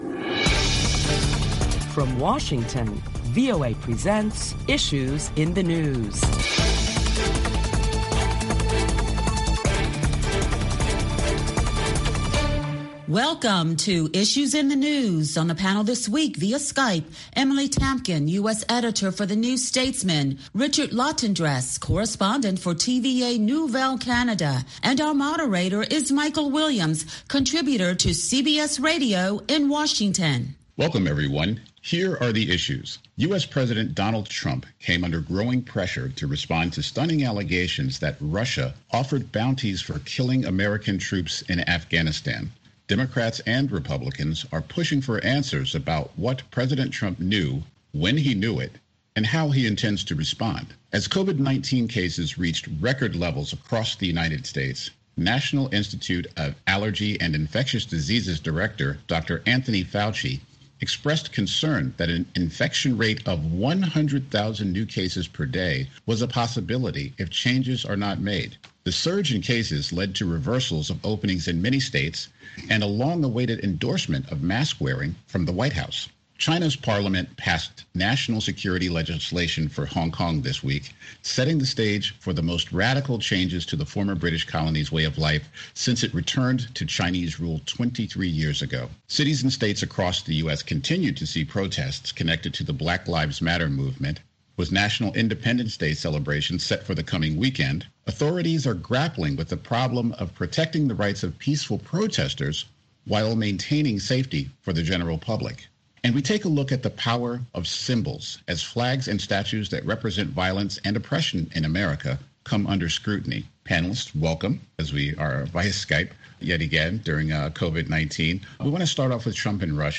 A panel of prominent Washington journalists deliberate the latest top stories of the week including the growing pressure for President Trump to respond to allegations that Russia offered bounties for killing American troops in Afghanistan.